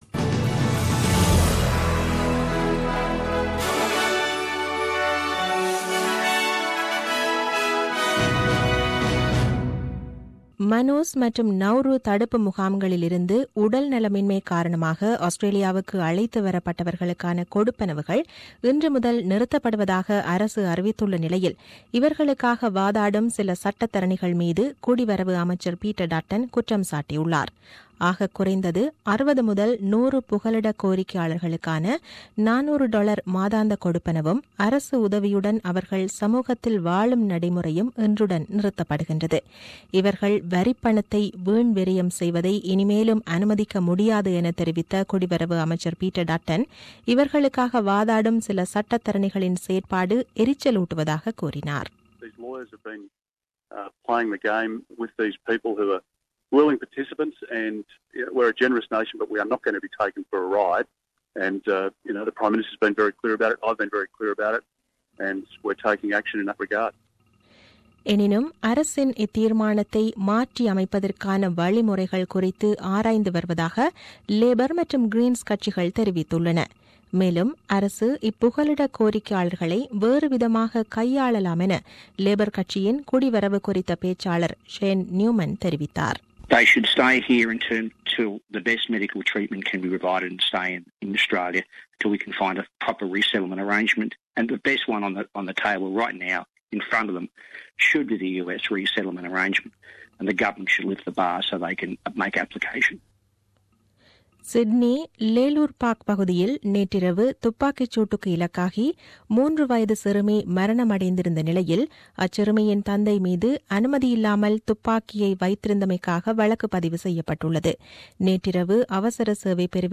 The news bulletin aired on 28 Aug 2017 at 8pm.